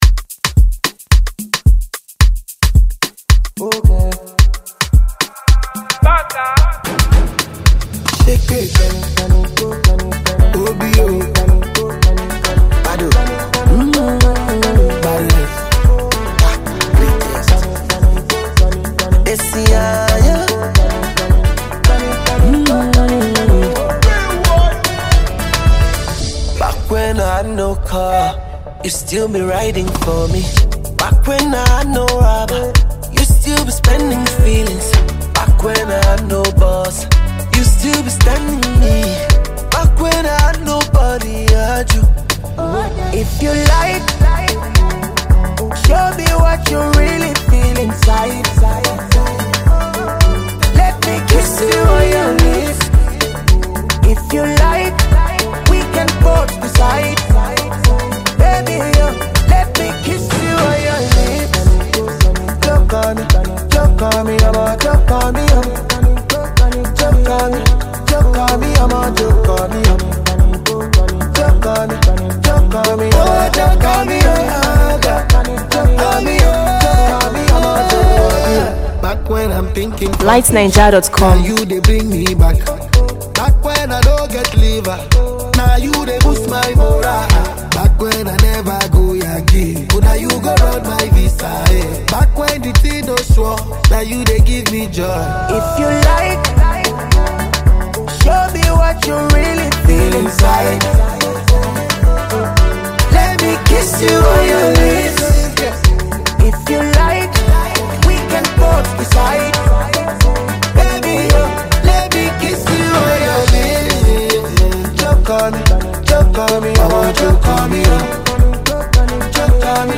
Talented Afro-pop Nigerian singer and song composer